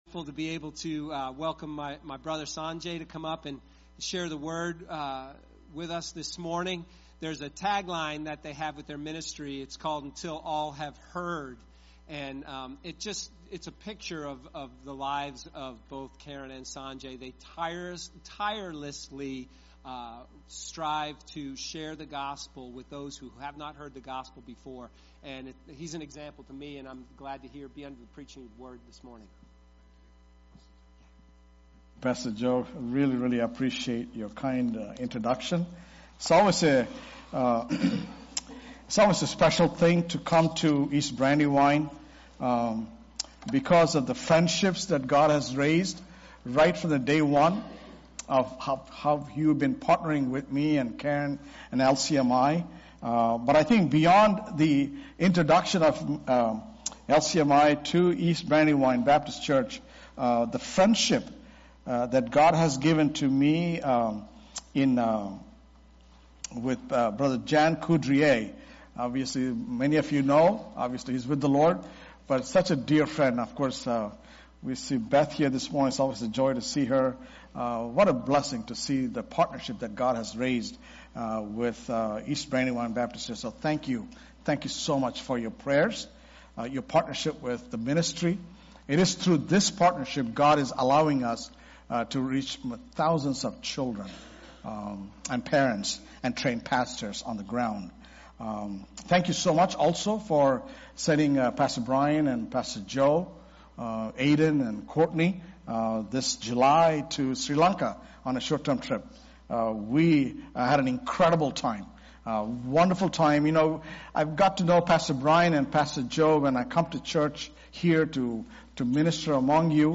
Sermons | East Brandywine Baptist Church